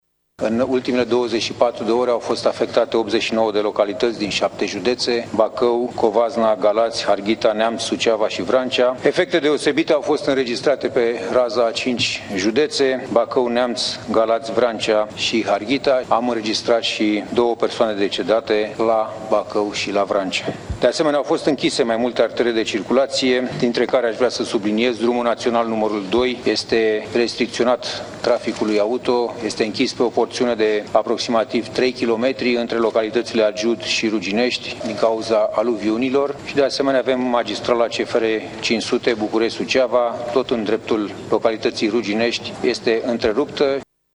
Așa arată ultimul bilanț prezentat de ministrul de interne, Petre Tobă, în cadrul videoconferinței cu prefecții.
4.500 de cadre MAI au fost mobilizate în ultimele 24 de ore, a subliniat ministrul Petre Tobă: